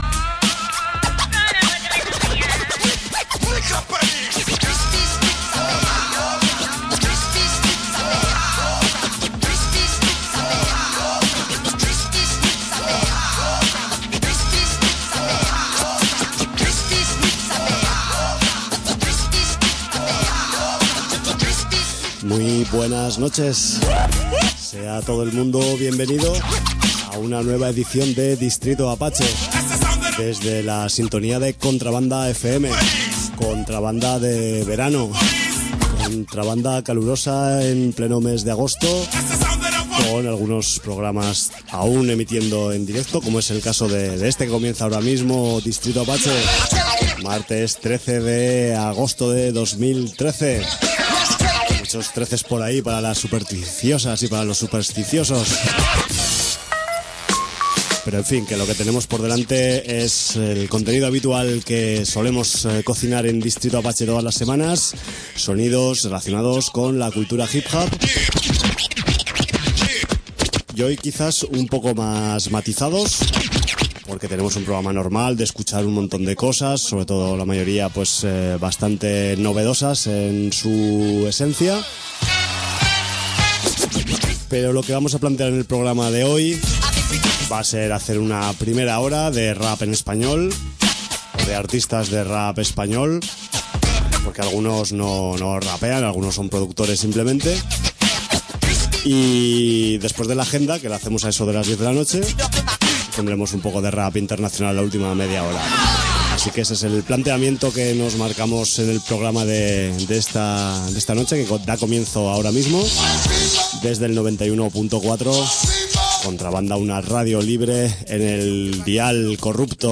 Hip Hop estatal